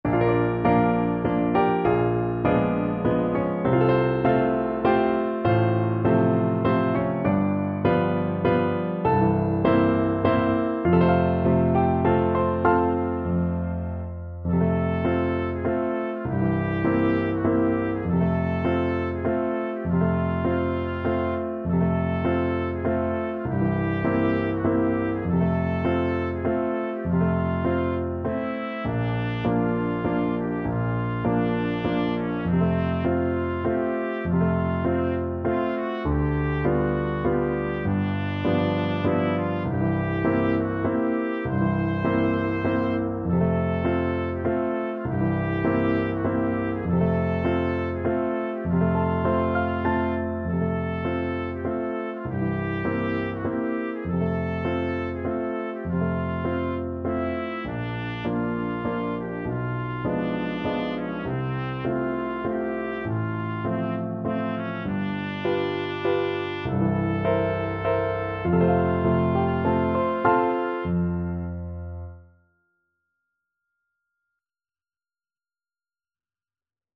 ~ = 100 Slowly and dreamily
3/4 (View more 3/4 Music)
Classical (View more Classical Trumpet Music)